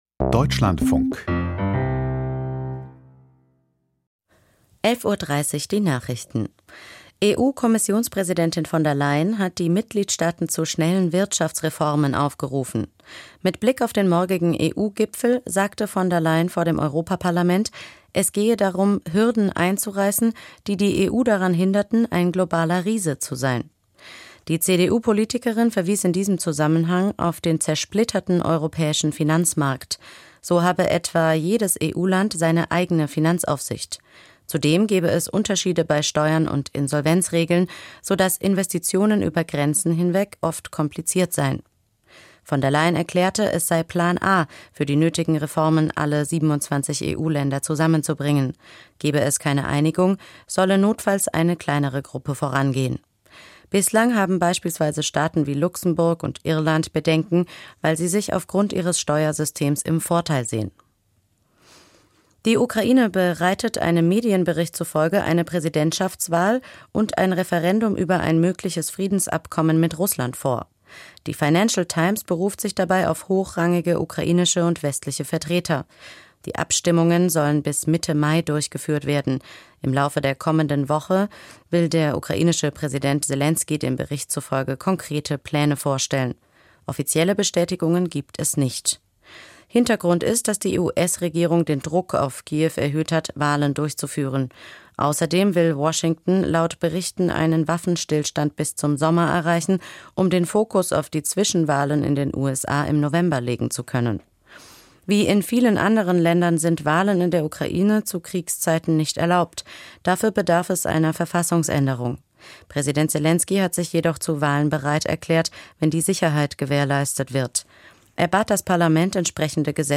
Die Nachrichten vom 11.02.2026, 11:30 Uhr